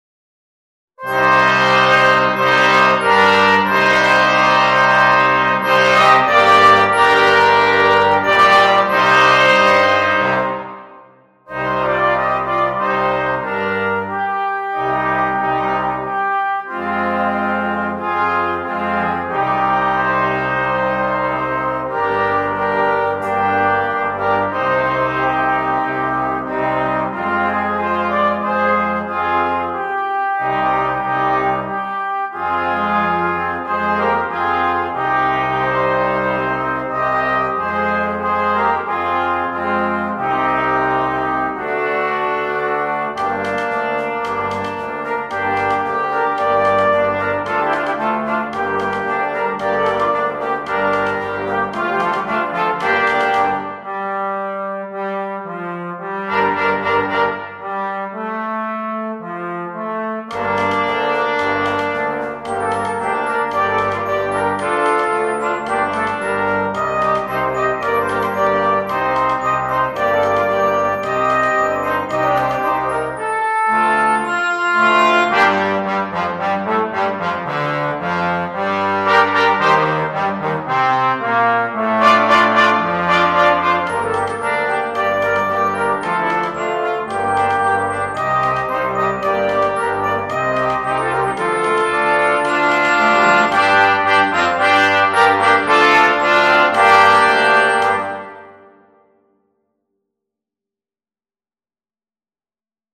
2. Junior Band (flex)
4 Parts & Percussion
without solo instrument
Entertainment
Percussion